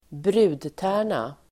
Uttal: [²br'u:dtä:r_na]